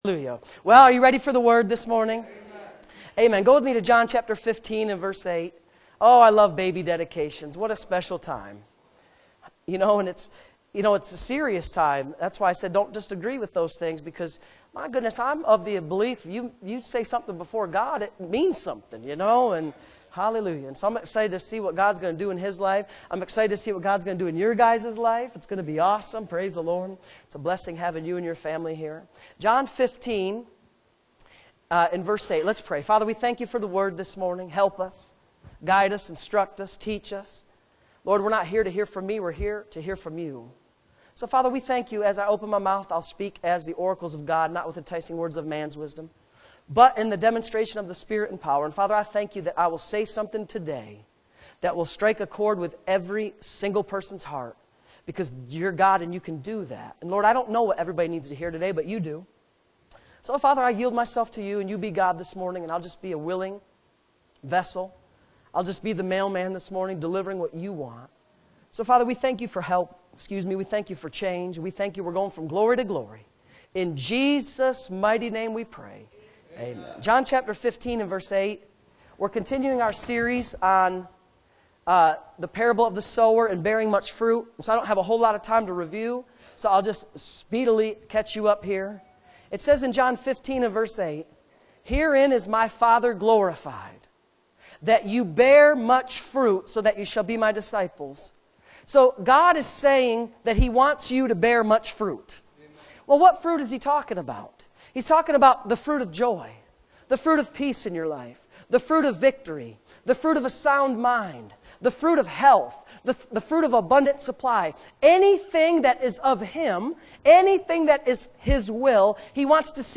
Bearing Much Fruit Tagged with Sunday Morning Services